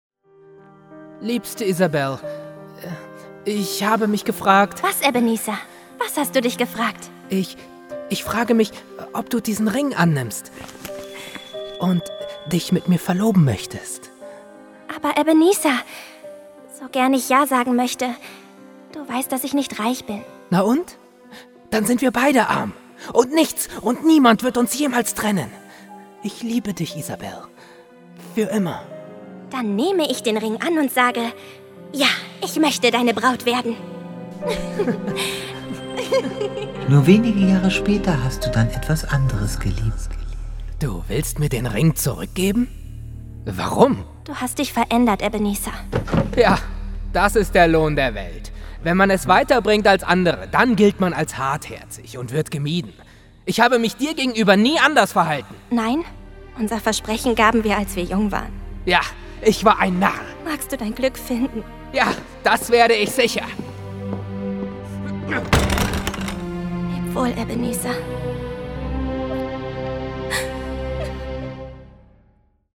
Hörspiel „Eine Weihnachtsgeschichte“
Rolle: Junger Scrooge
Hoerprobe-Weihnachtsgeschichte_RolleJungerScrooge.mp3